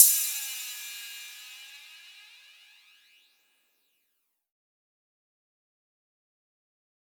Crashes & Cymbals
Cym southern style.wav